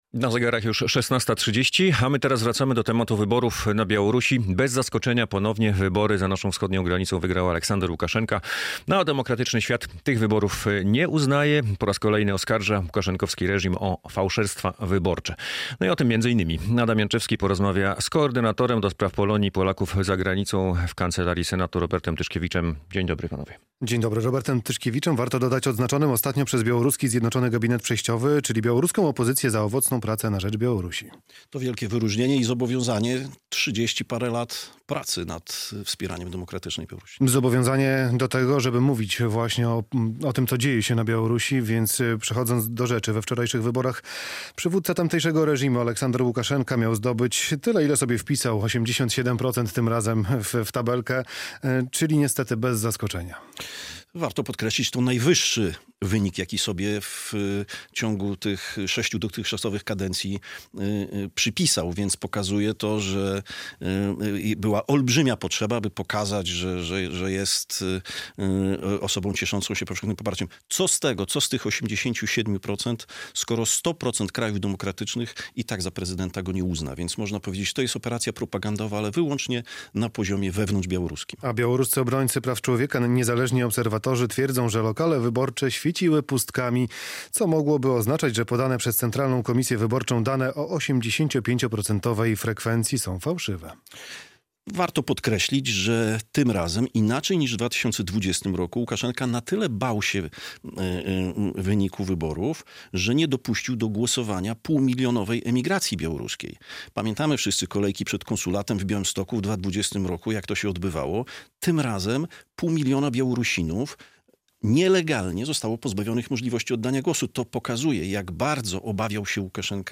Radio Białystok | Gość | Robert Tyszkiewicz - koordynator do spraw Polonii i Polaków za granicą w kancelarii Senatu RP